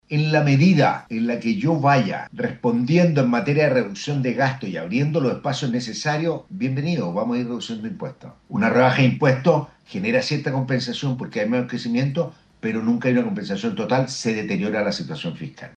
El expresidente del Banco Central, José de Gregorio, dijo en Expreso Bio Bio, que en efecto, esto tendría impactos en el crecimiento, pero en lo inmediato y con este contexto fiscal de fondo, puede deteriorar aun más la situación.